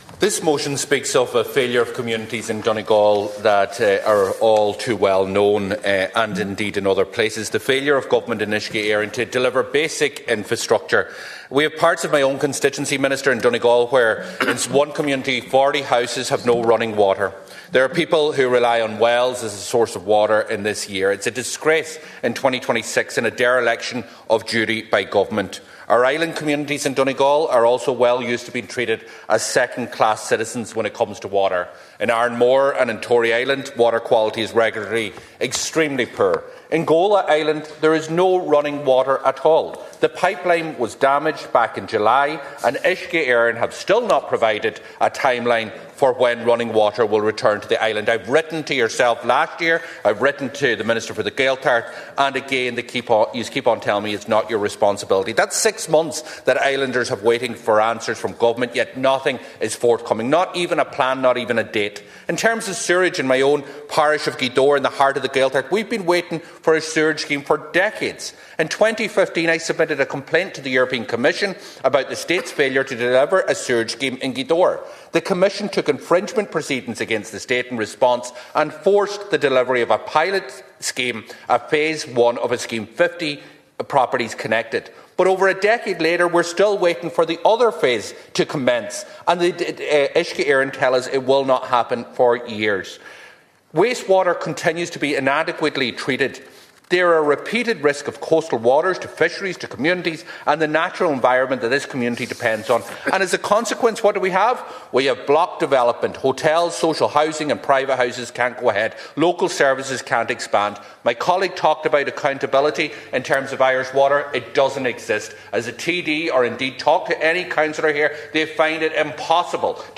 Speaking during a  Dail debate on water infrastructure,  Deputy Pearse Doherty pointed to several issues in the county.